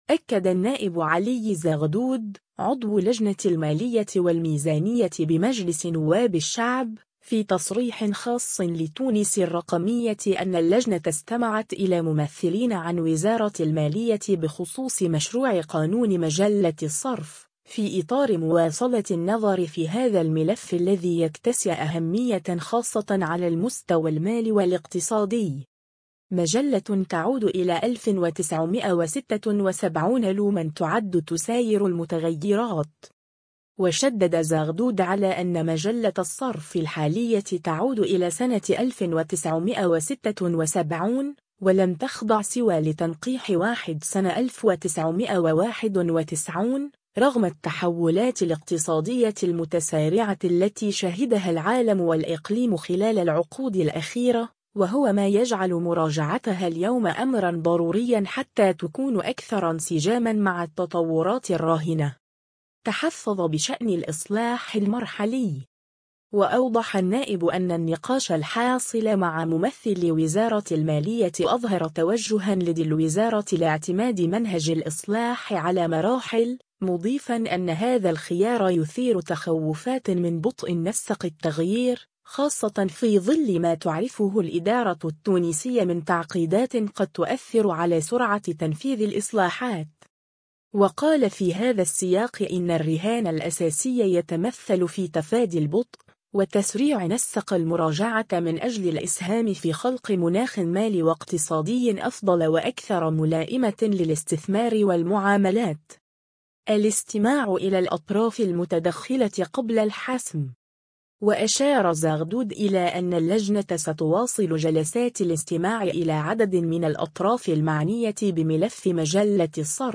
أكد النائب علي زغدود، عضو لجنة المالية والميزانية بمجلس نواب الشعب، في تصريح خاص لـ”تونس الرقمية” أن اللجنة استمعت إلى ممثلين عن وزارة المالية بخصوص مشروع قانون مجلة الصرف، في إطار مواصلة النظر في هذا الملف الذي يكتسي أهمية خاصة على المستوى المالي والاقتصادي.